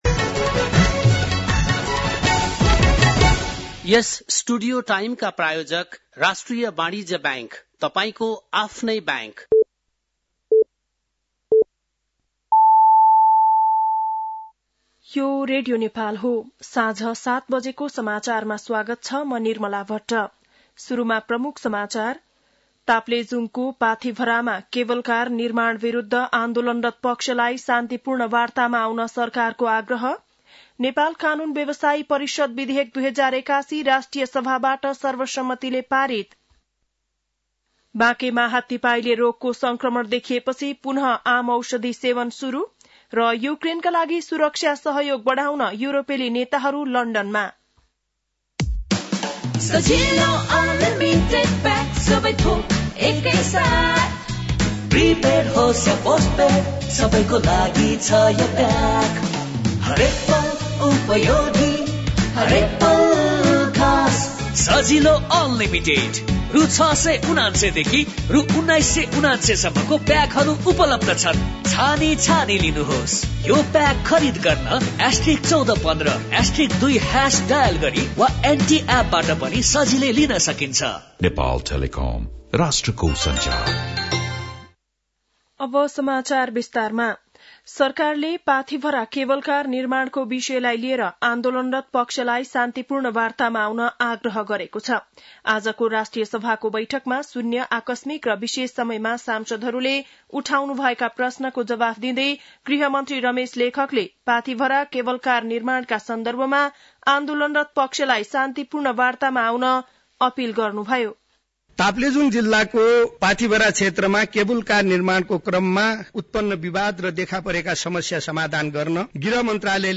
बेलुकी ७ बजेको नेपाली समाचार : १९ फागुन , २०८१
7-pm-news.mp3